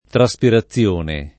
[ tra S pira ZZL1 ne ]